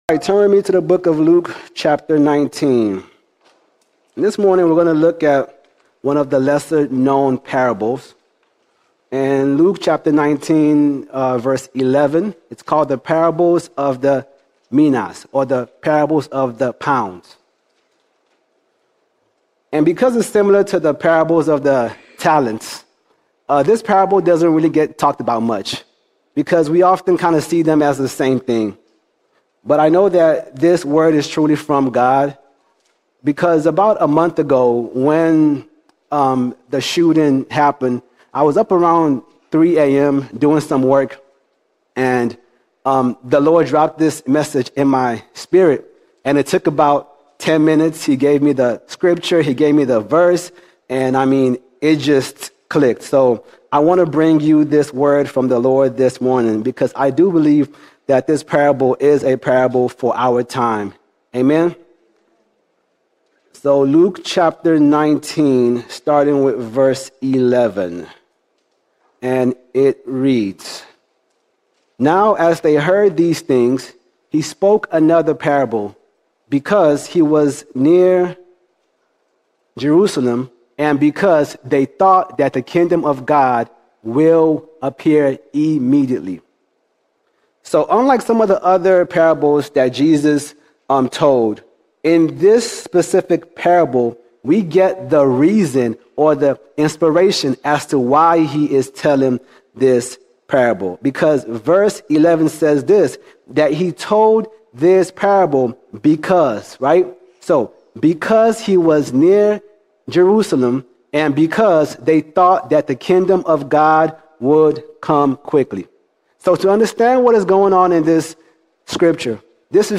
6 October 2025 Series: Sunday Sermons All Sermons Slow Me Down Slow Me Down When the world grows darker and many are tempted to give up, Jesus calls us to stay engaged.